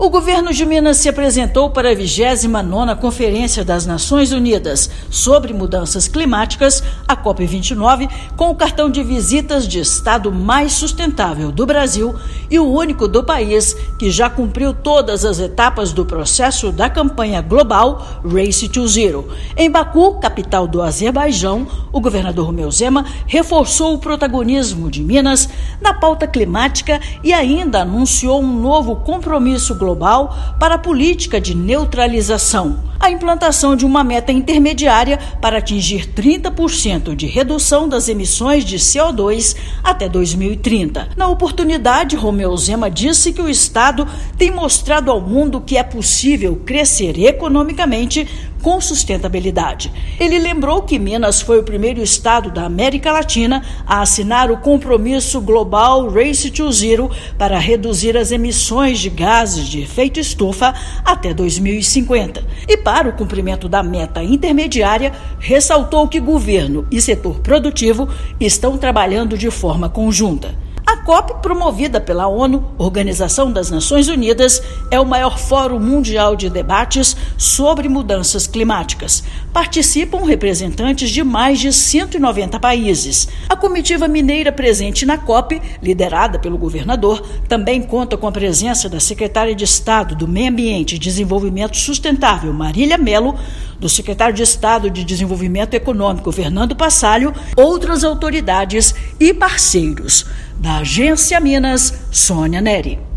Estado mais sustentável do Brasil traça meta intermediária para antecipar resultados da política de redução: diminuir 30% até 2030. Ouça matéria de rádio.